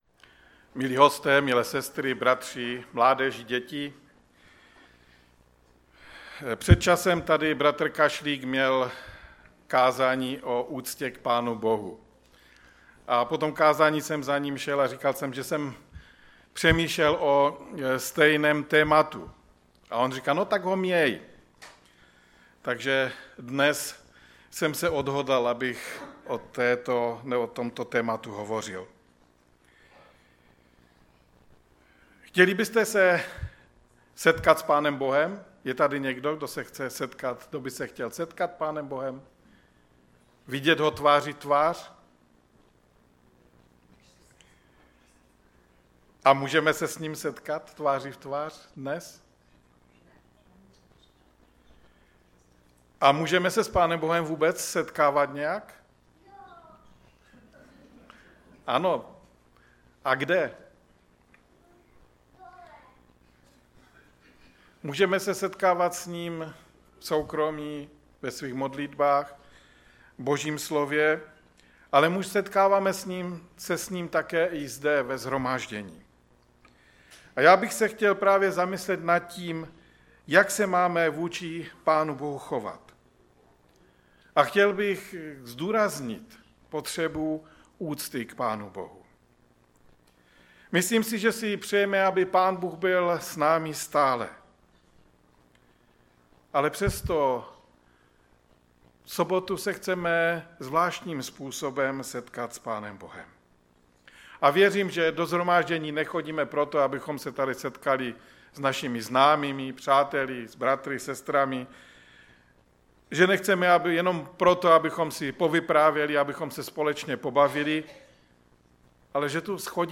ve sboře Ostrava-Radvanice.